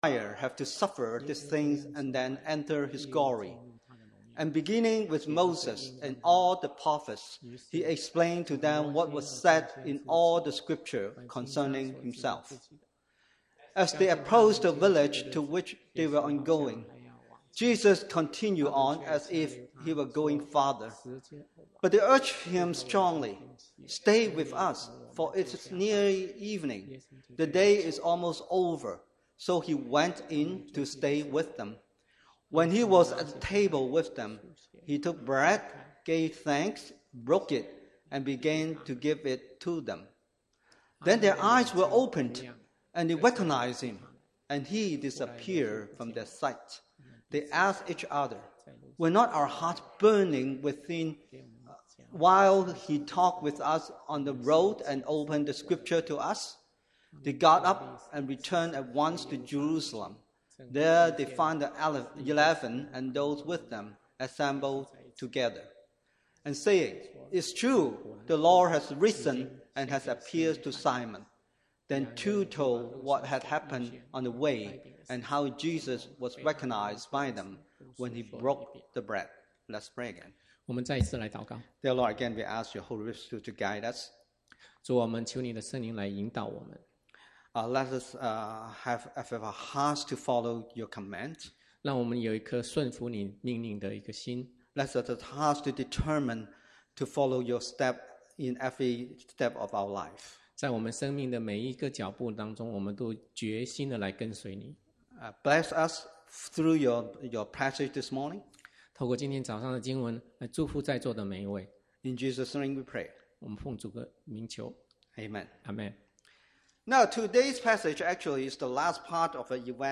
過去的佈道 - 美亞美華人浸信教會